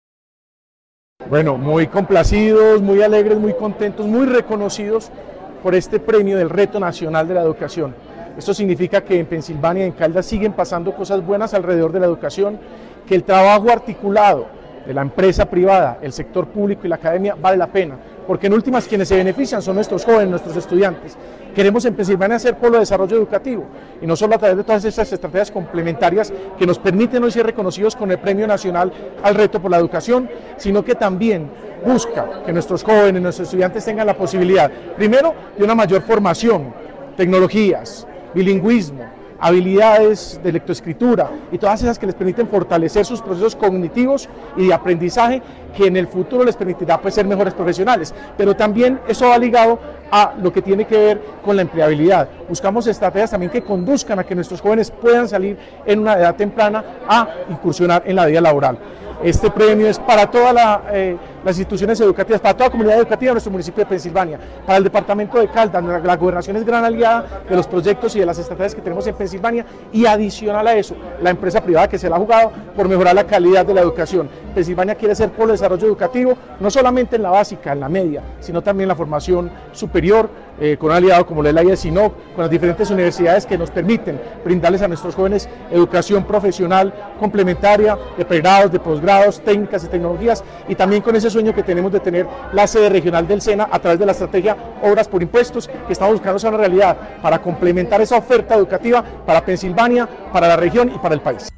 Alcalde de Pensilvania, Jesús Iván Ospina.
Alcalde-de-Pensilvania-Jesus-Ivan-Ospina.mp3